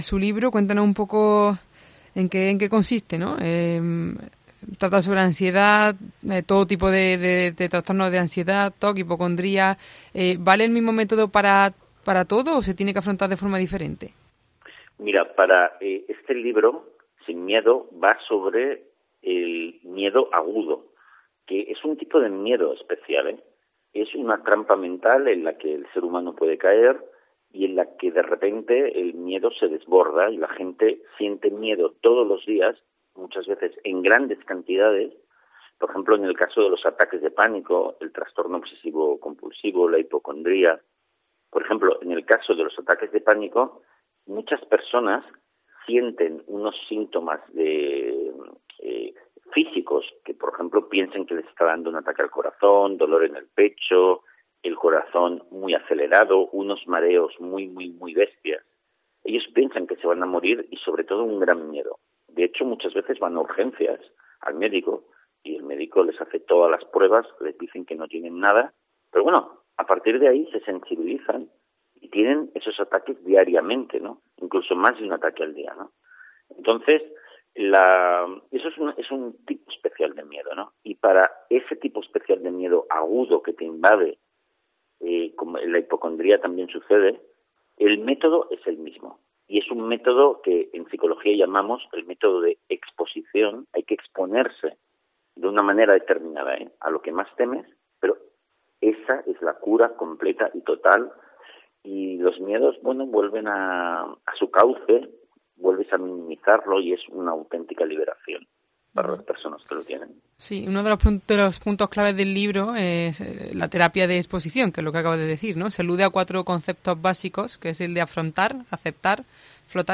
Escucha íntegra la entrevista al psicólogo Rafael Santandreu